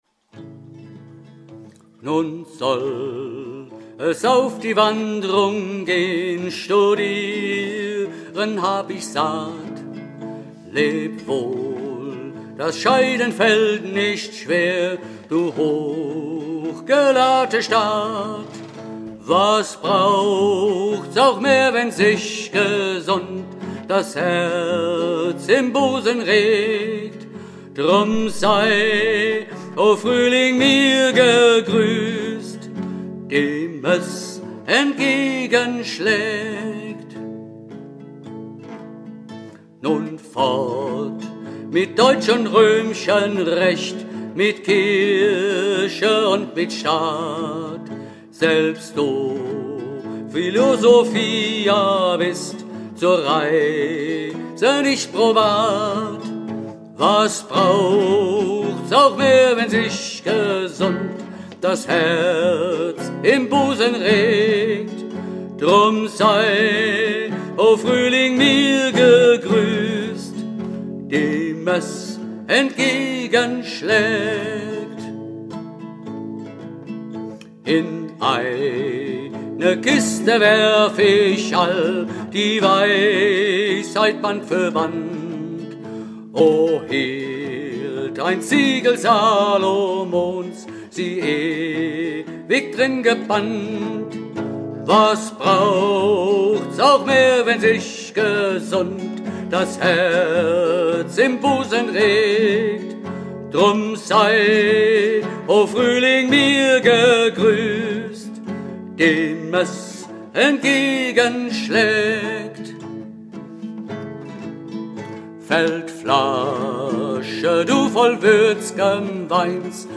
>>> Hörprobe : MIDI von 2014 [6.740 KB] - mp3